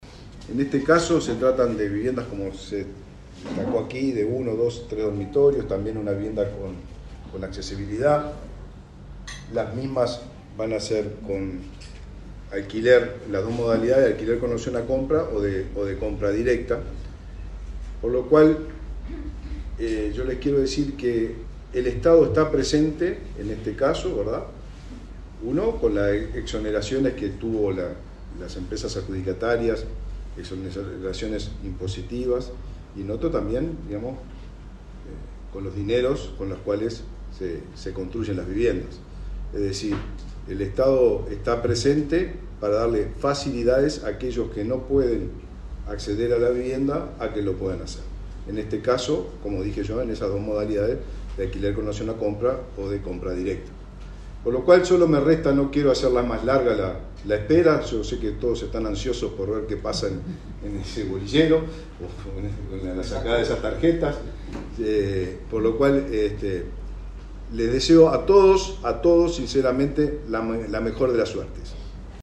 Palabras del ministro de Vivienda, Raúl Lozano
Palabras del ministro de Vivienda, Raúl Lozano 22/08/2023 Compartir Facebook X Copiar enlace WhatsApp LinkedIn Este martes 22, el ministro de Vivienda, Raúl Lozano, participó en Canelones del sorteo entre inscriptos para acceder a vivienda en la localidad de Las Piedras.